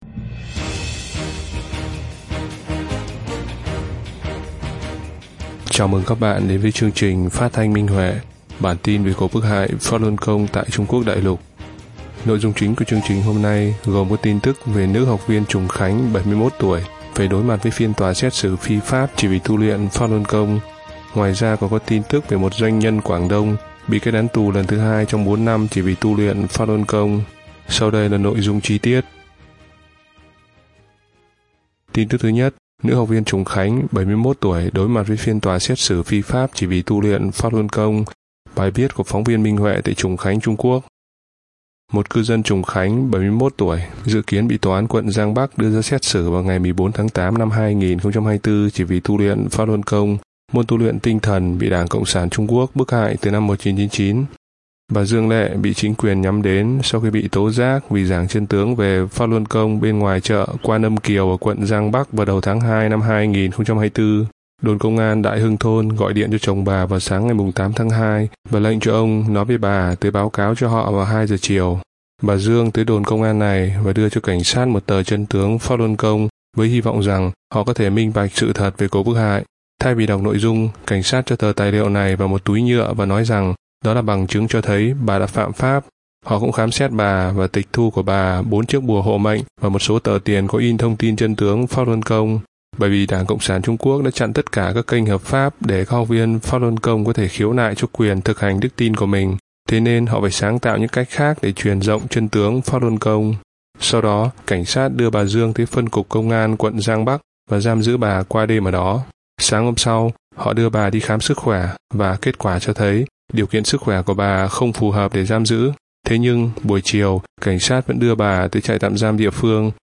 Chương trình phát thanh số 125: Tin tức Pháp Luân Đại Pháp tại Đại Lục – Ngày 1/8/2024